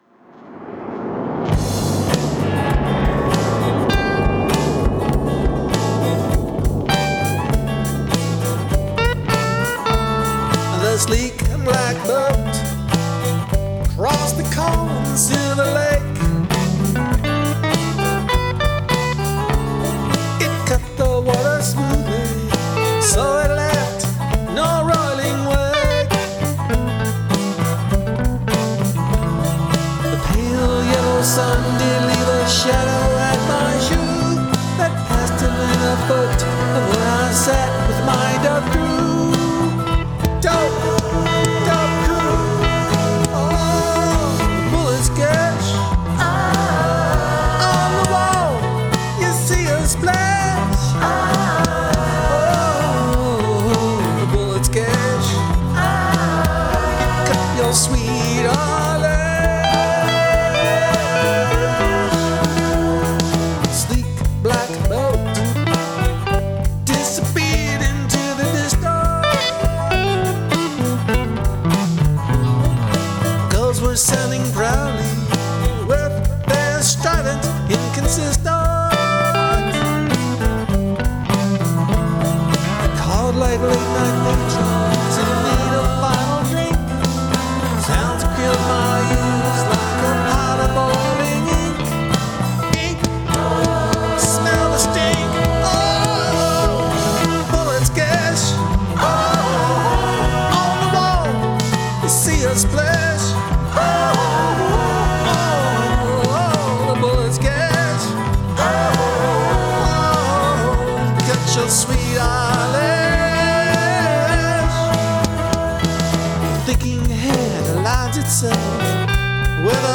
I use live drums and a gate on the snare to trigger some additional samples. I also take 3 copies of the snare and treat them and blend in after taste.